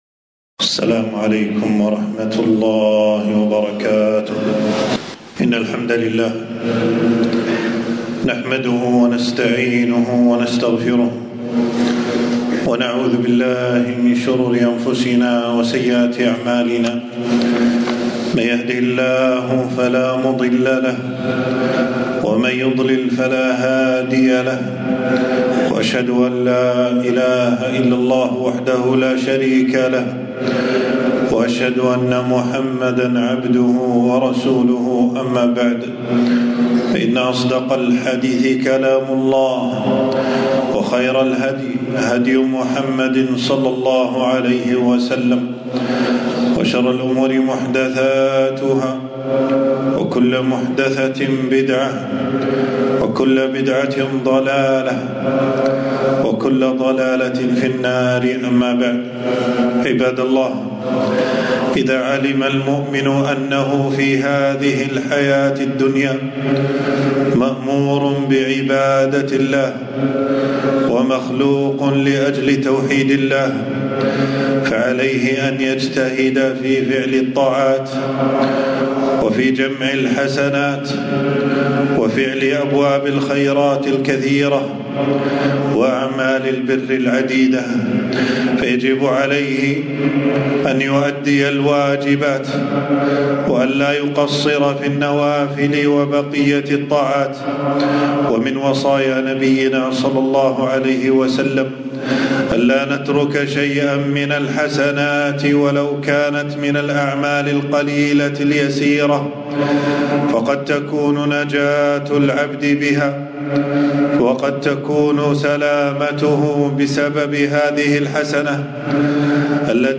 خطبة - اتق النار ولو بشق تمرة